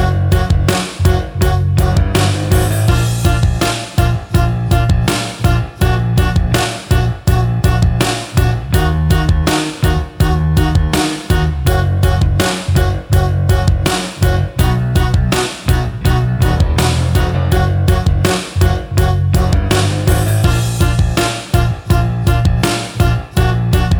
no choir Backing Vocals Indie / Alternative 4:17 Buy £1.50